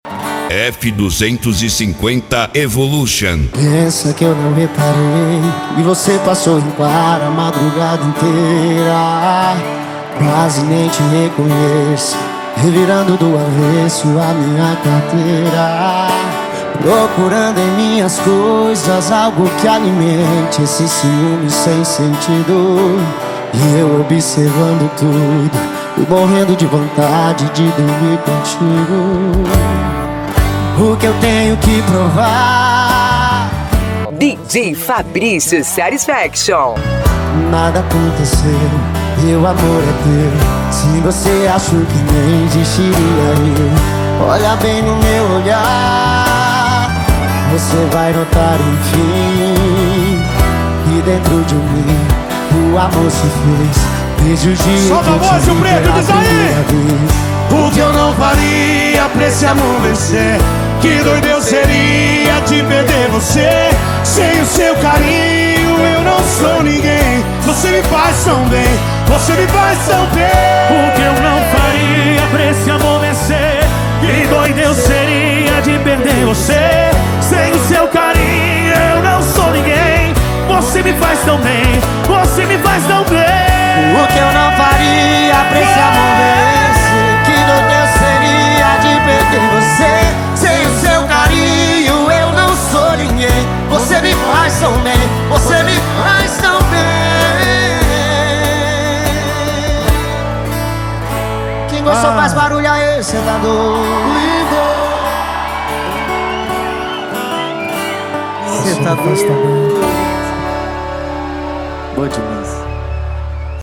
Modao
SERTANEJO